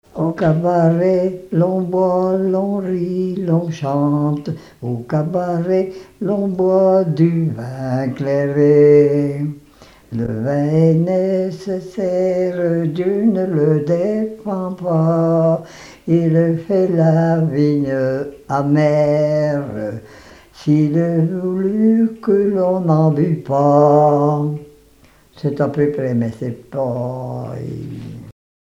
chansons et témoignages parlés
Pièce musicale inédite